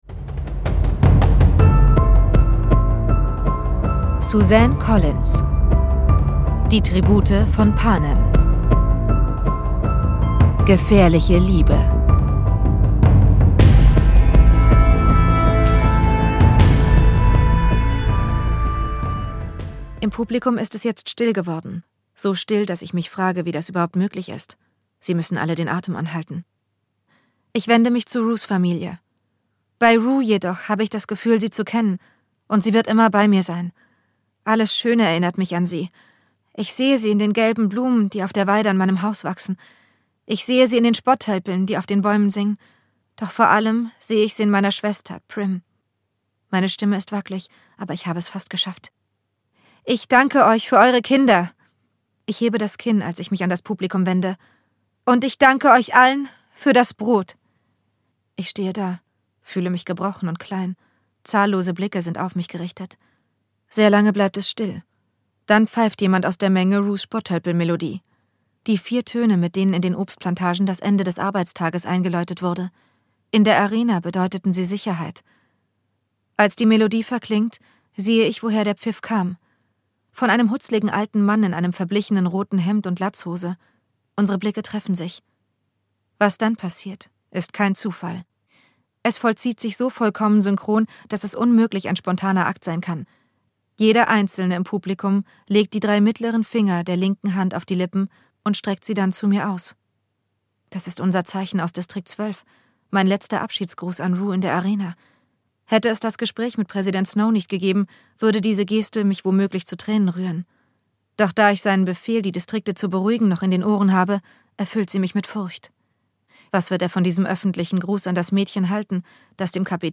Hörbuch: Die Tribute von Panem 2.